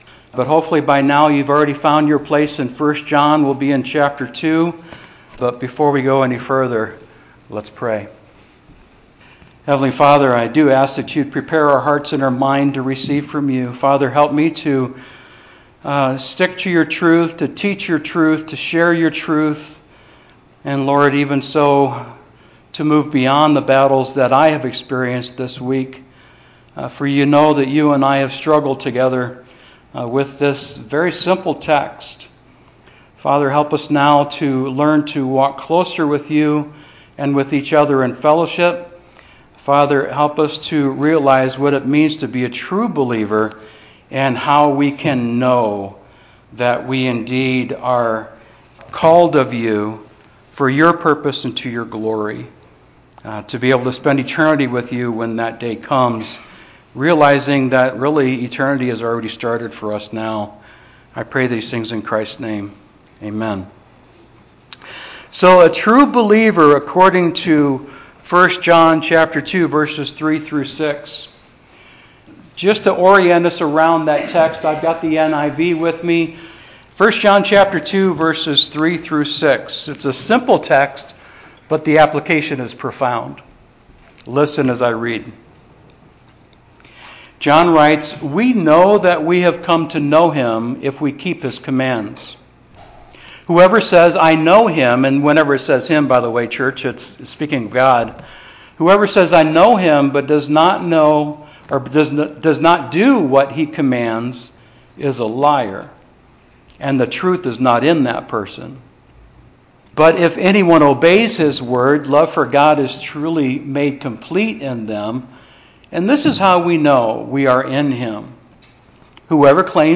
Sermon Outline: A True Believer . . . 1. Knows the Word (v. 3) 2. Stands Firm in the Truth (v. 4) 3. Loves God (v. 5) 4. Lives for Eternity (v. 6)